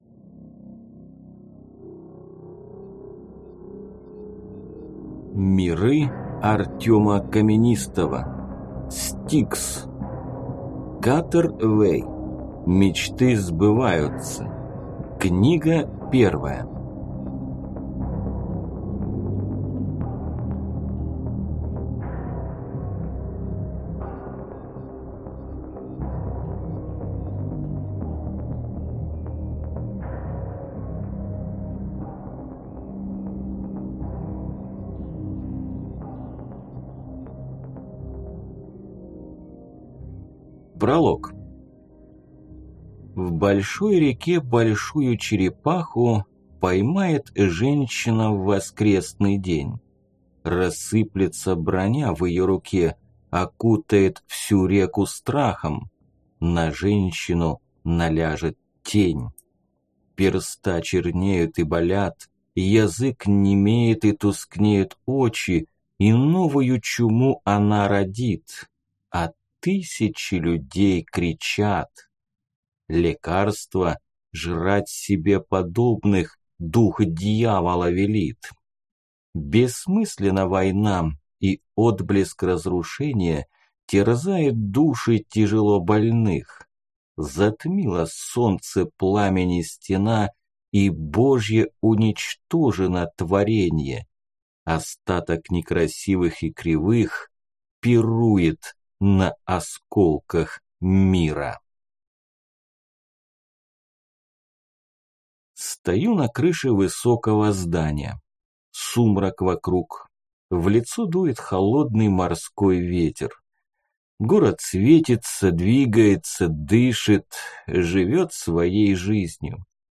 Аудиокнига S-T-I-K-S. Мечты сбываются | Библиотека аудиокниг